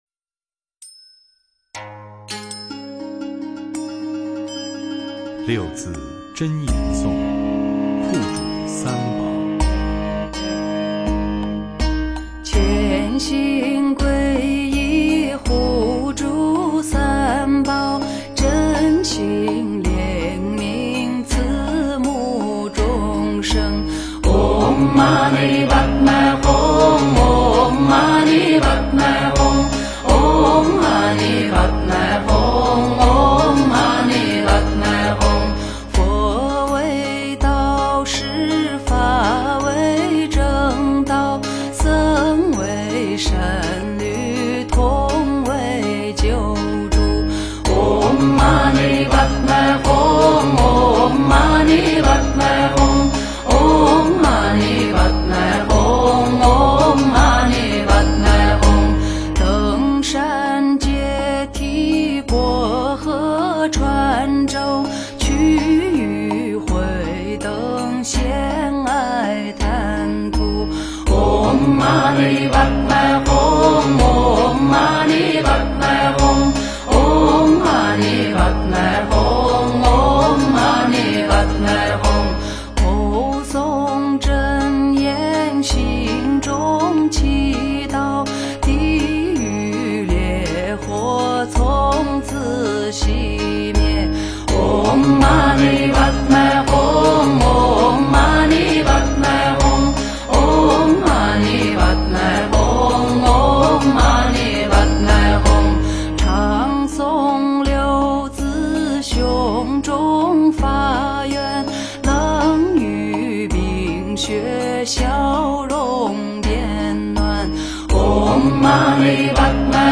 六字真言颂-六字断除六道苦难颂 诵经 六字真言颂-六字断除六道苦难颂--汉语 点我： 标签: 佛音 诵经 佛教音乐 返回列表 上一篇： 般若波罗蜜多心经(达摩配乐精选) 下一篇： 清净法身佛 相关文章 莲花生大士心咒--唱经给你听 莲花生大士心咒--唱经给你听...